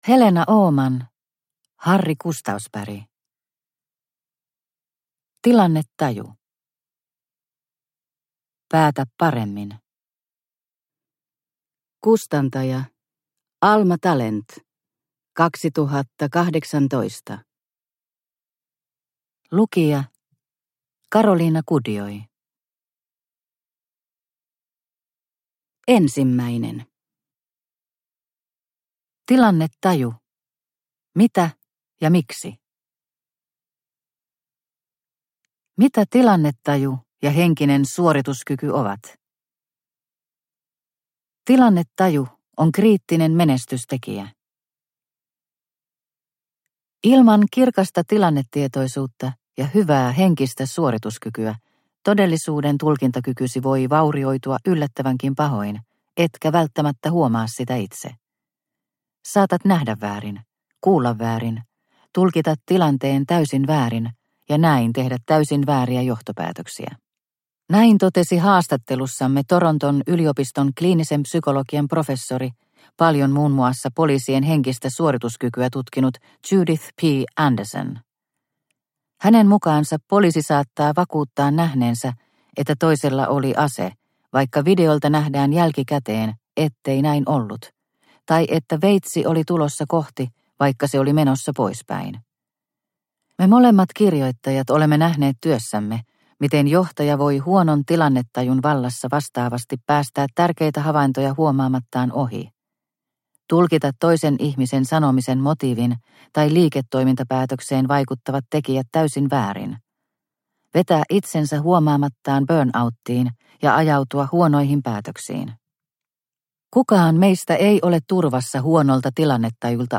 Tilannetaju – Ljudbok – Laddas ner